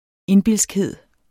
Udtale [ ˈenˌbilˀsgˌheðˀ ]